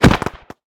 Minecraft Version Minecraft Version snapshot Latest Release | Latest Snapshot snapshot / assets / minecraft / sounds / entity / player / attack / crit2.ogg Compare With Compare With Latest Release | Latest Snapshot